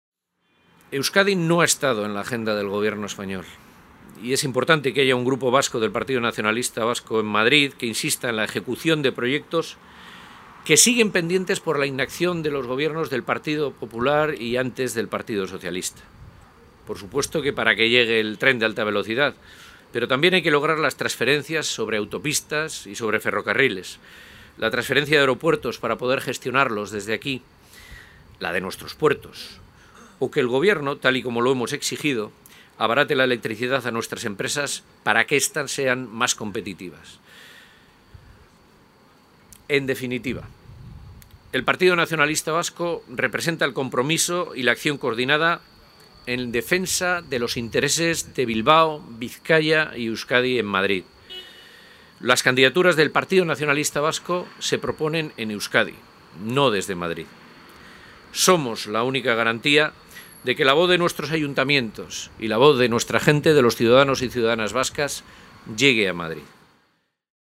En un acto en la plaza Doctor Fleming de Bilbao, y arropado por el alcalde Juan Mari Aburto, el diputado general de Bizkaia, Unai Rementeria, y la presidenta del Bizkai Buru Batzar, Itxaso Atutxa, Aitor Esteban ha destacado la colaboración interinstitucional jeltzale.